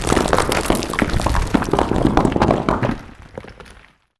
Stones_falls.ogg